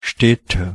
Ääntäminen
Ääntäminen most common: IPA: /ˈʃtɛtə/ Haettu sana löytyi näillä lähdekielillä: saksa Käännöksiä ei löytynyt valitulle kohdekielelle. Städte on sanan Stadt monikko.